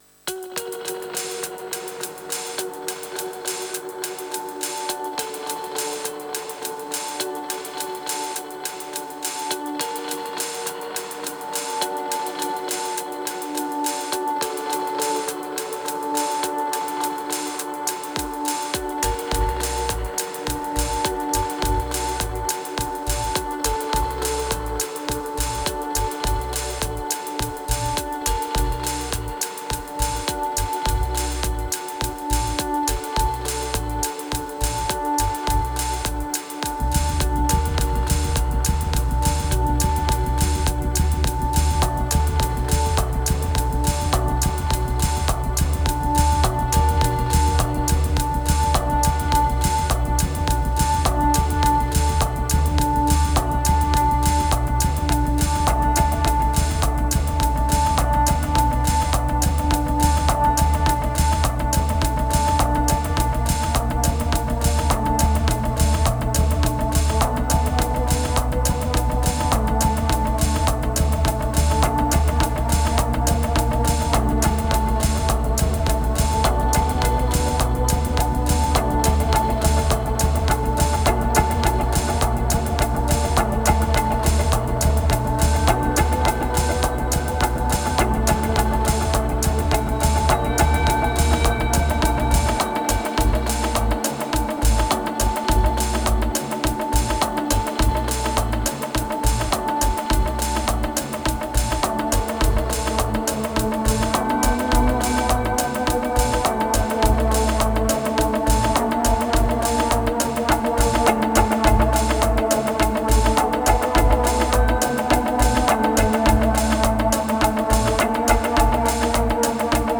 1784📈 - 92%🤔 - 52BPM🔊 - 2023-09-09📅 - 307🌟
3 takes.
A bit of a eQ.